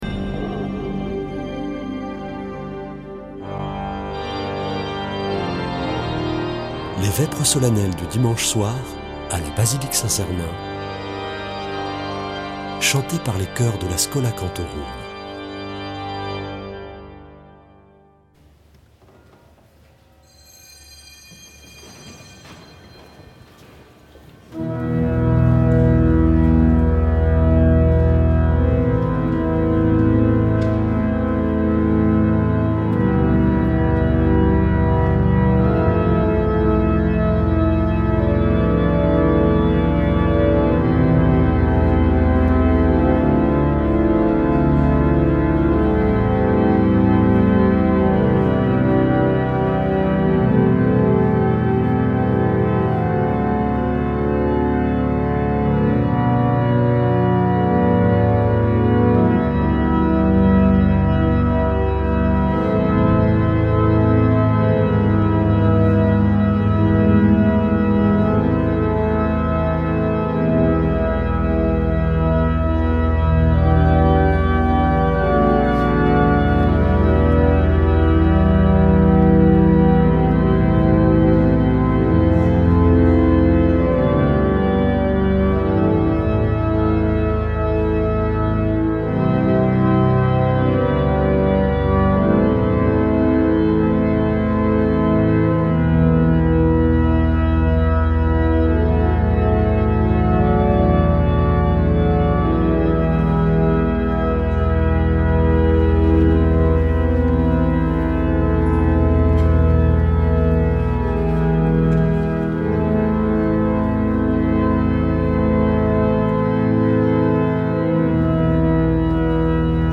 Vêpres de Saint Sernin du 07 janv.
Une émission présentée par Schola Saint Sernin Chanteurs